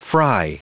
Prononciation du mot fry en anglais (fichier audio)
Prononciation du mot : fry